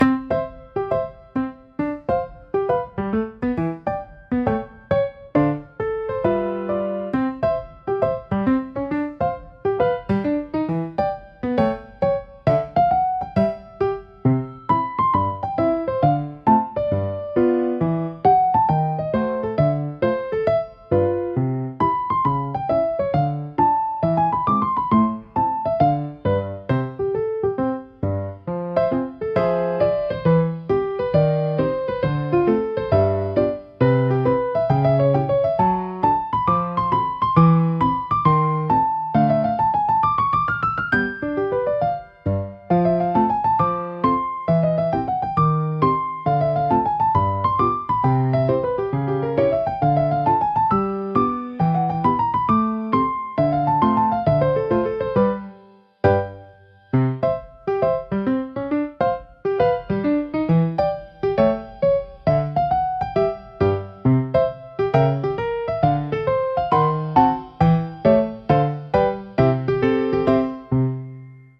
ピアノの柔らかなタッチが集中をサポートし、疲れを癒す効果を発揮します。